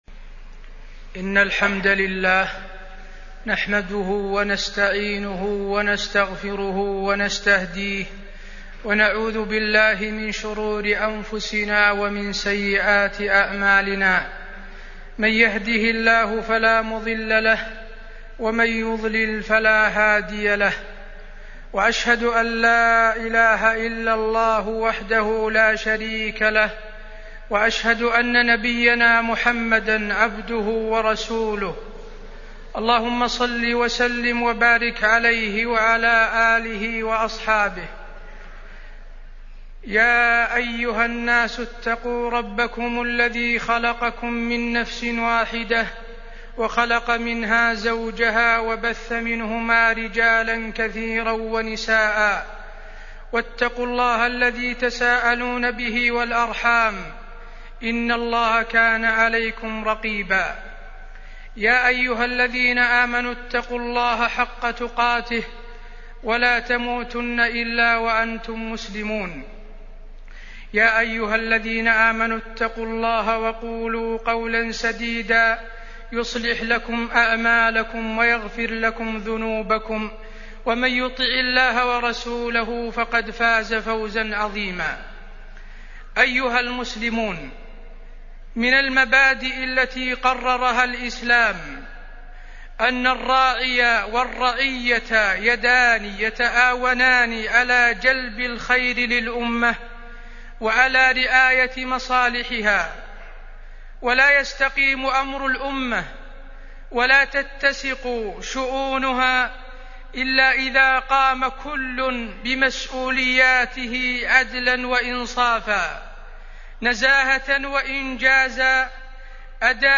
عنوان الخطبة التاريخ الرشوة